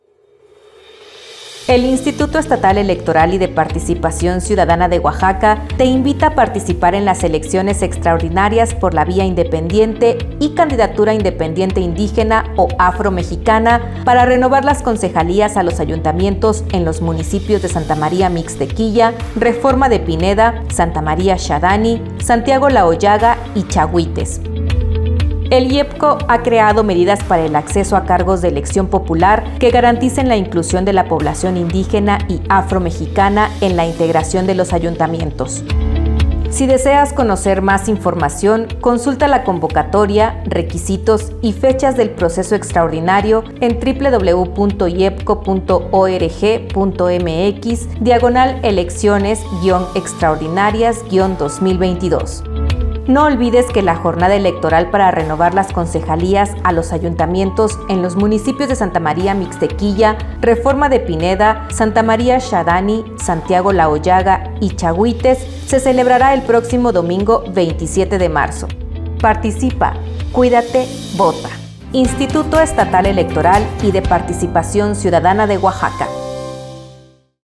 Spot Concejalías